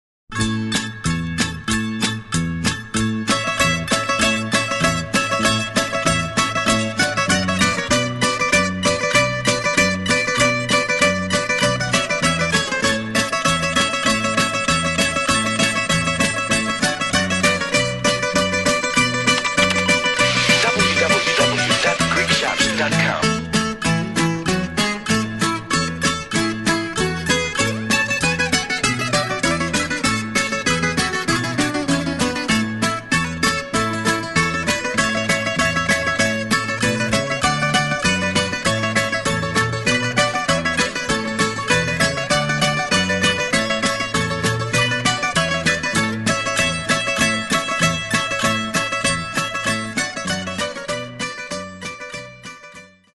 14 great instrumentals from a great composer
Bouzouki soloist